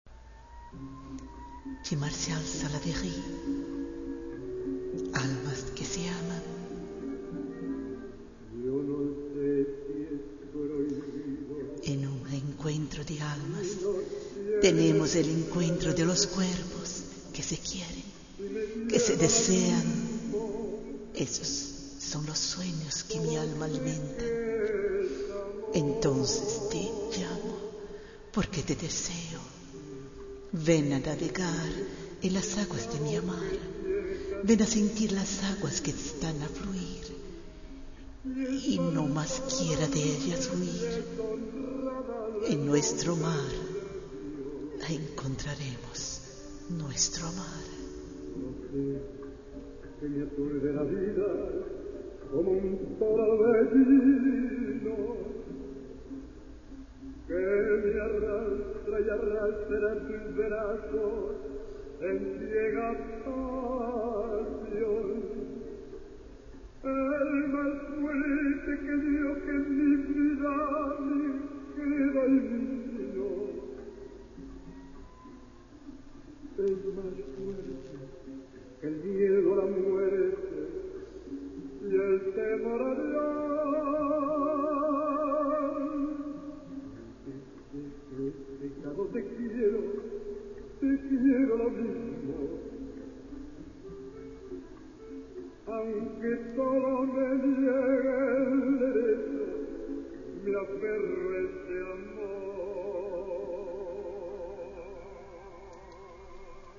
Si desea escuchar la música de fondo después de la Lectura de
arte final e voz: